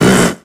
infinitefusion-e18 / Audio / SE / Cries / SPOINK.ogg
SPOINK.ogg